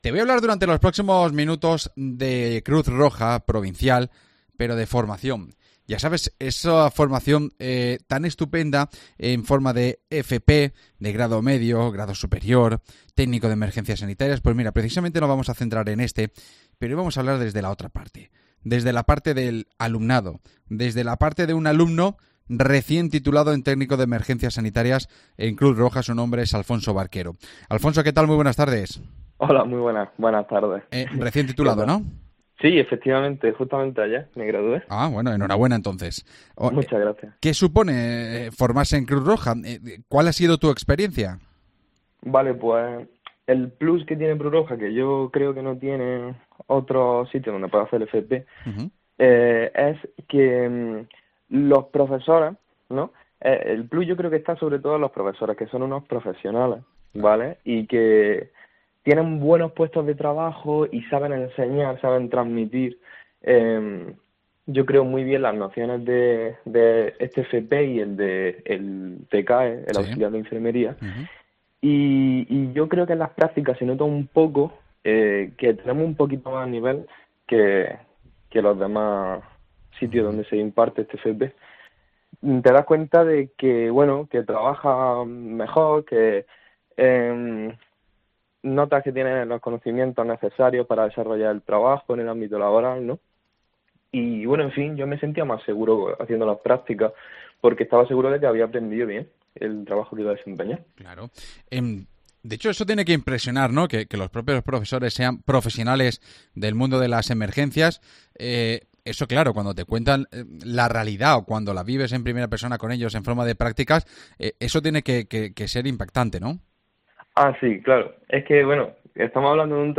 AUDIO: Hablamos con un recién titulado que cuenta su experiencia de formación